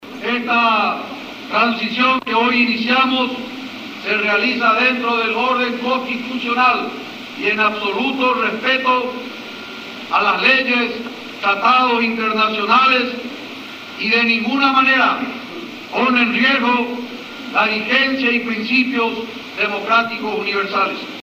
Declaraciones de el nuevo presidente de Paraguay, Federico Franco